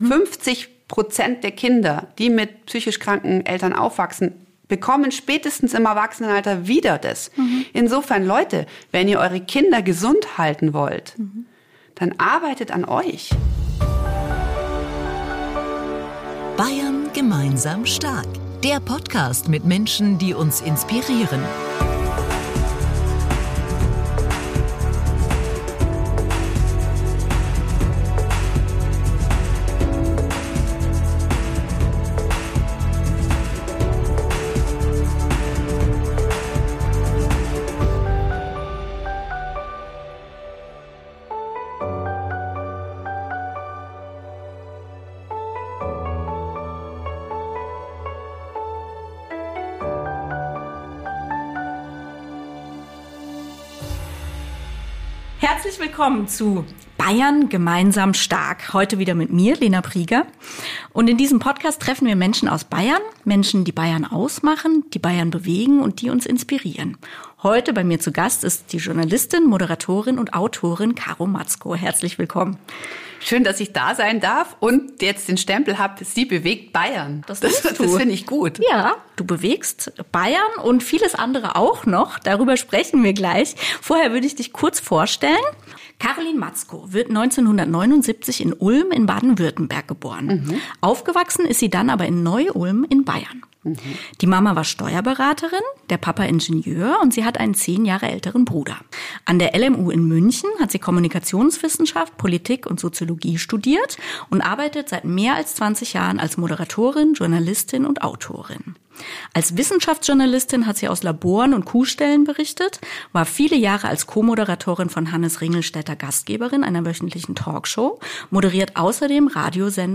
Wieviel auch auf den zweiten Blick in der quirligen Frau steckt, zeigt ihr Einsatz für soziale Themen, ihre Veröffentlichungen als Autorin und nicht zuletzt unser Podcast-Gespräch...